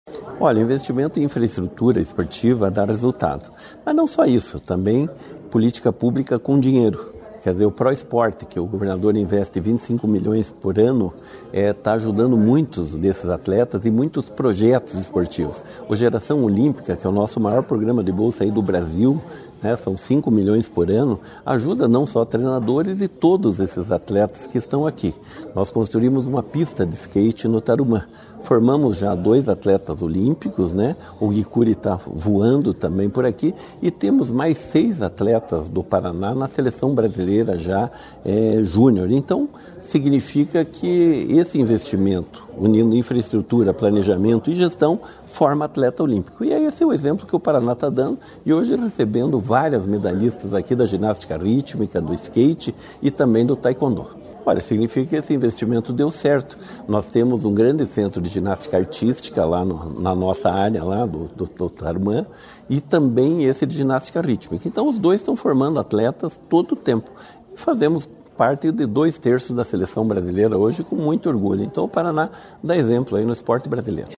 Sonora do secretário Estadual do Esporte, Helio Wirbiski, sobre o novo Complexo de Treinamento de Skate e Ginástica, em Londrina